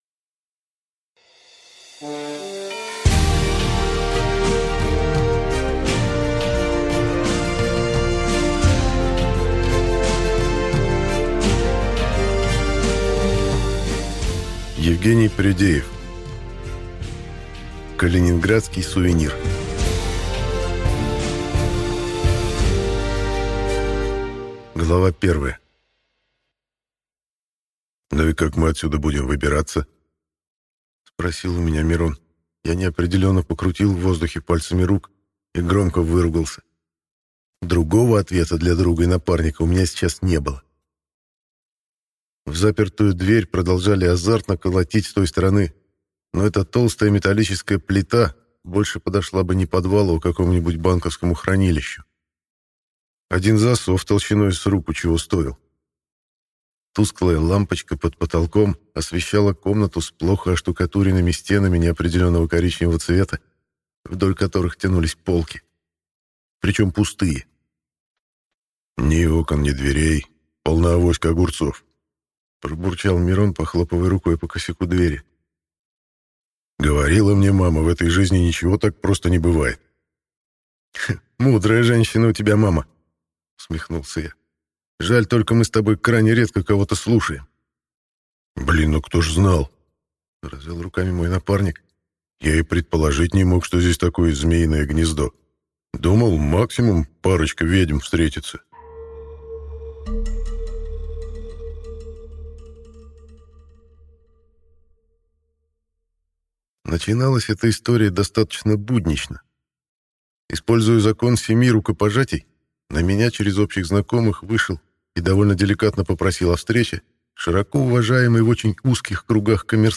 Аудиокнига Калининградский сувенир | Библиотека аудиокниг